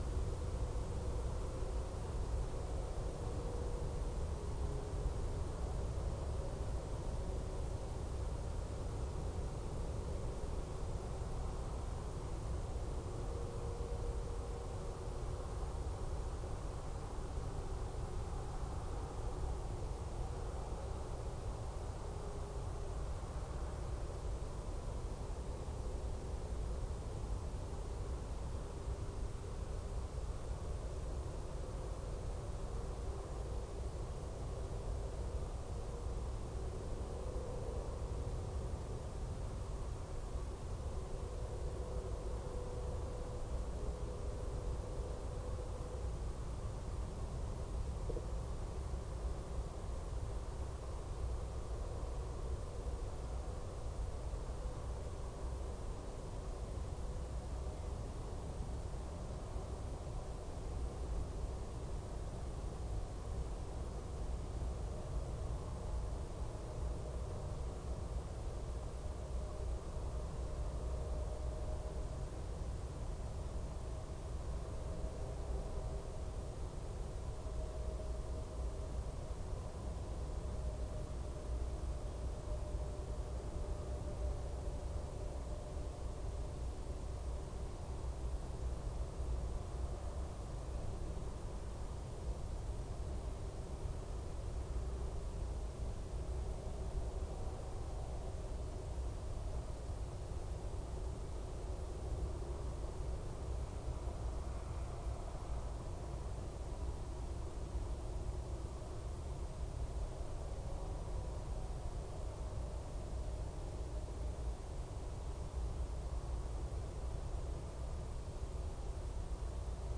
Corvus corone
unknown bird
Parus major
Emberiza citrinella
Alauda arvensis